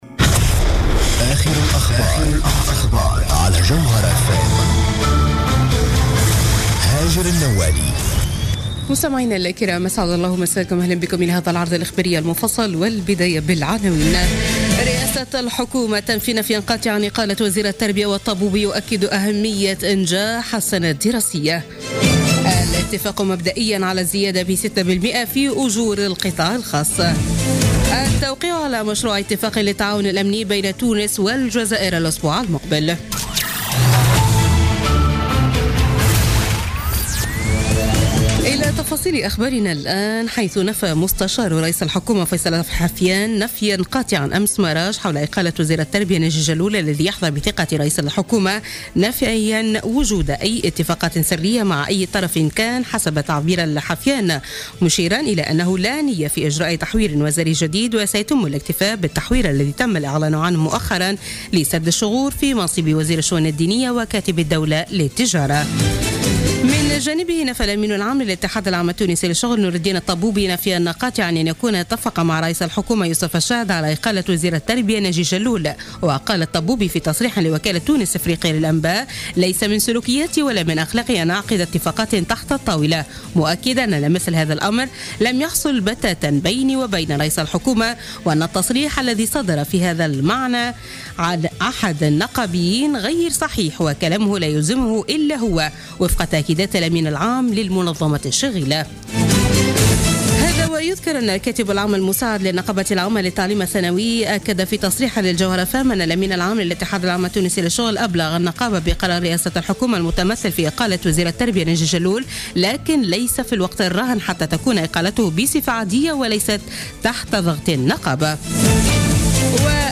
نشرة أخبار منتصف الليل ليوم الأحد 5 مارس 2017